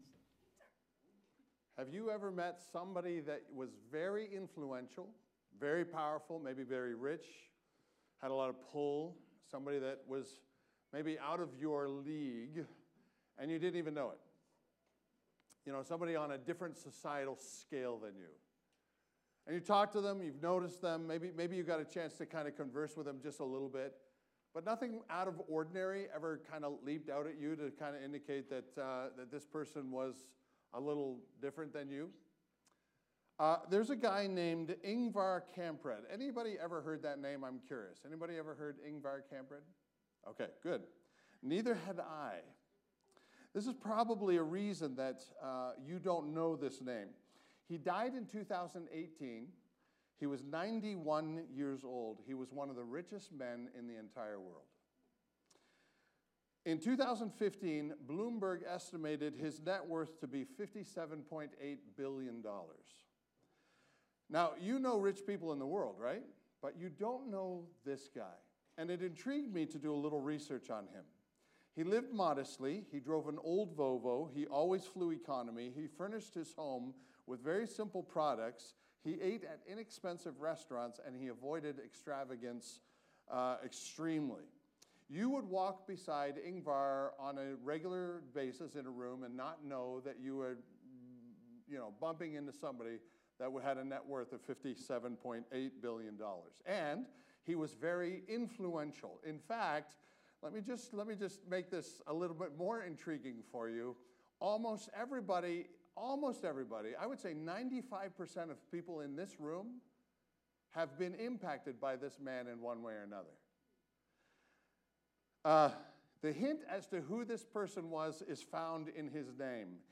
Village Church East: Sermons